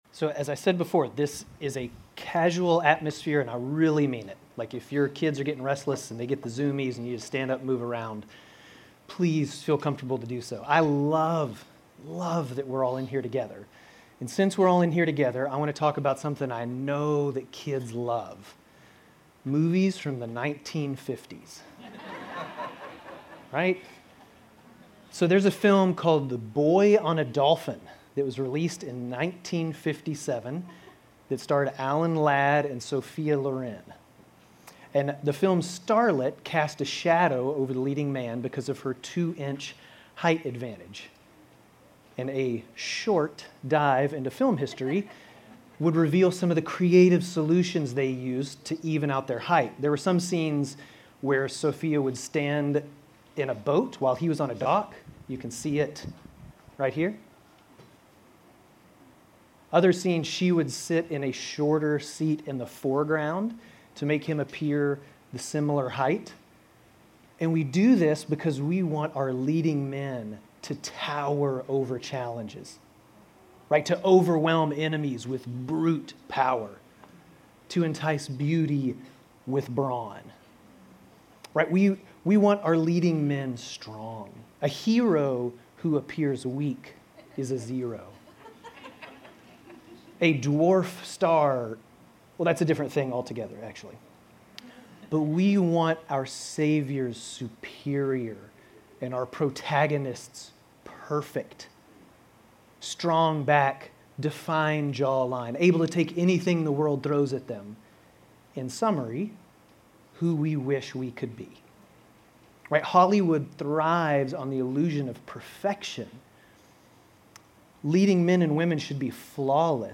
Grace Community Church Dover Campus Sermons Gen 25:29-34, Gen 27:1-41 - Jacob and Essau Birthright and Blessing Nov 10 2024 | 00:20:24 1x 00:00 / 00:20:24 Subscribe Share RSS Feed Share Link Embed